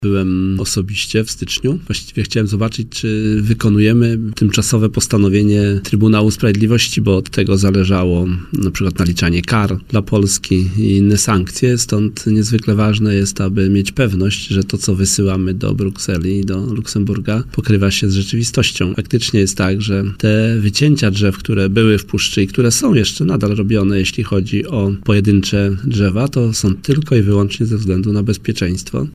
Minister Środowiska, Henryk Kowalczyk, zaznaczył, w poranku „Siódma9” na antenie Radia Warszawa, że osobiście w styczniu nadzorował prace w Puszczy Białowieskiej.